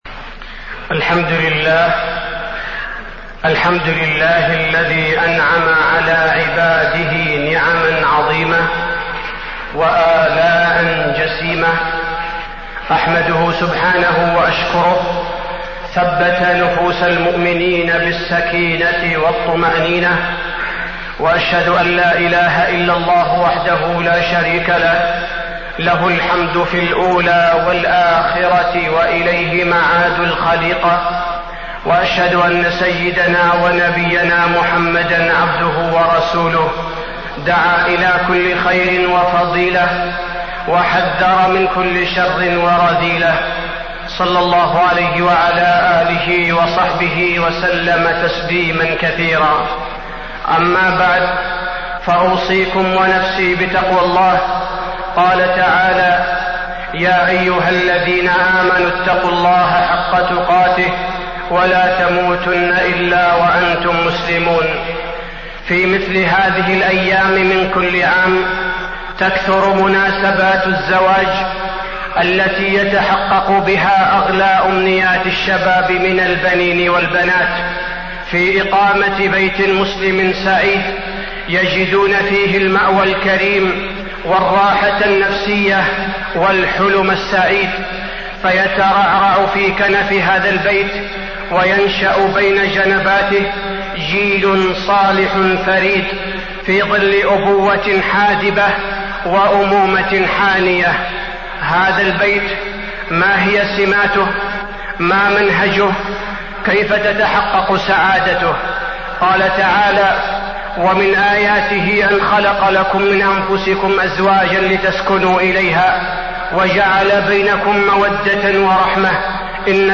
تاريخ النشر ٢٨ جمادى الآخرة ١٤٢٨ هـ المكان: المسجد النبوي الشيخ: فضيلة الشيخ عبدالباري الثبيتي فضيلة الشيخ عبدالباري الثبيتي البيت المسلم وحفظه